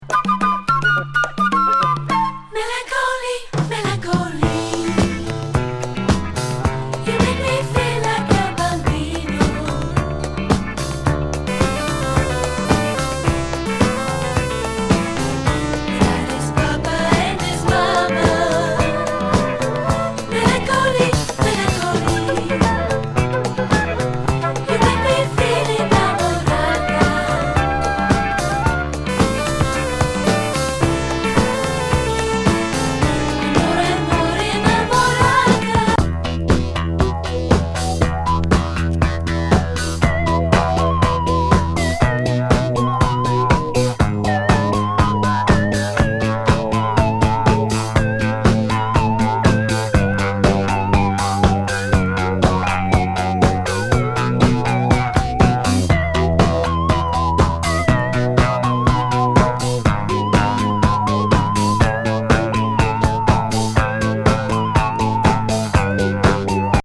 女性コーラス入りムード音楽ディスコ
変コズミック・ディスコ